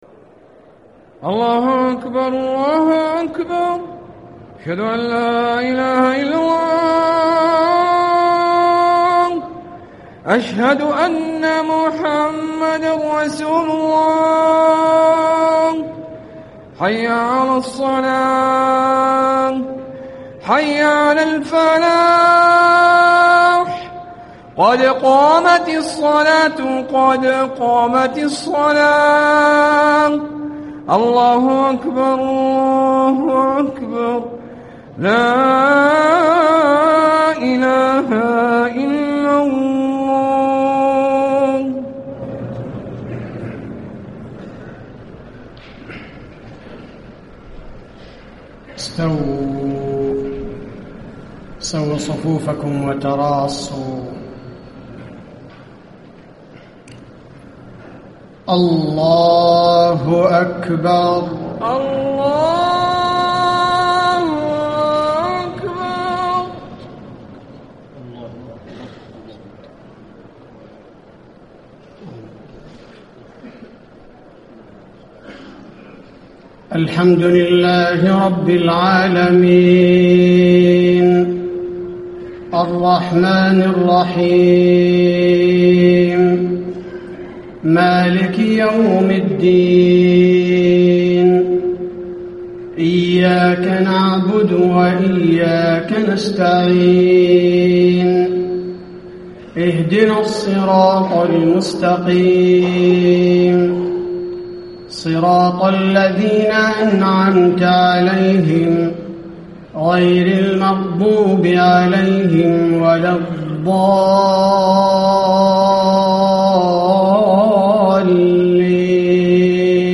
صلاة العشاء 8-6- 1435 ما تيسر من سورة الحديد > 1435 🕌 > الفروض - تلاوات الحرمين